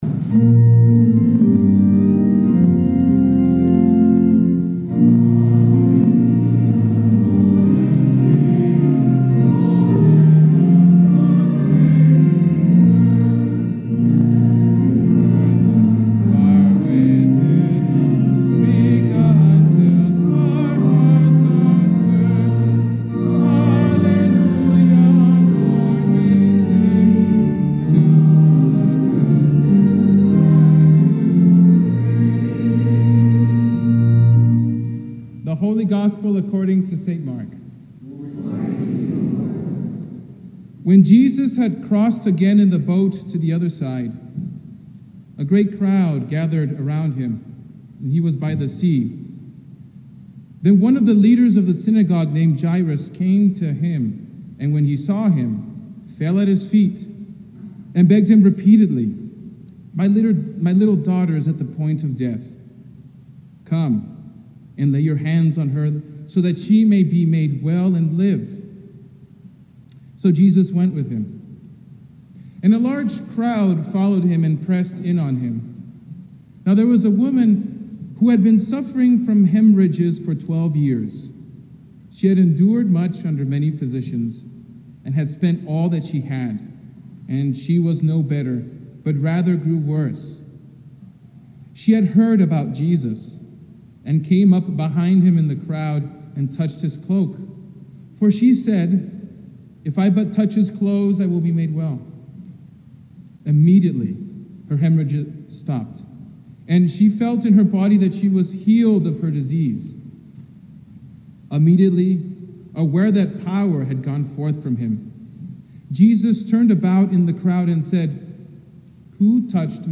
Sermon from the Sixth Sunday After Pentecost